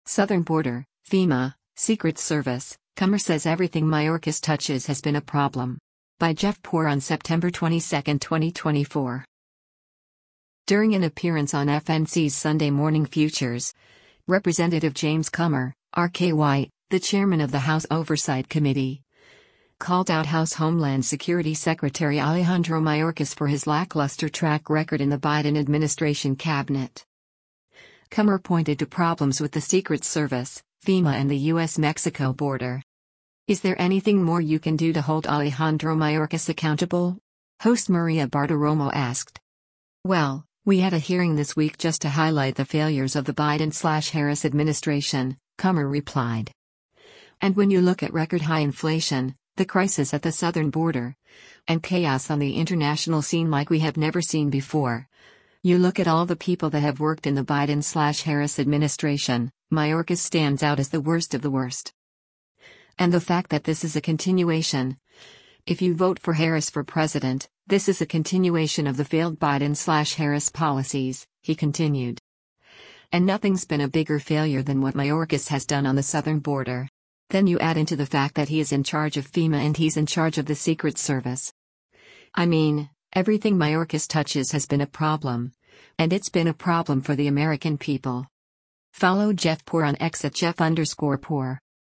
During an appearance on FNC’s “Sunday Morning Futures,” Rep. James Comer (R-KY), the chairman of the House Oversight Committee, called out House Homeland Security Secretary Alejandro Mayorkas for his lackluster track record in the Biden administration Cabinet.
“Is there anything more you can do to hold Alejandro Mayorkas accountable?” host Maria Bartiromo asked.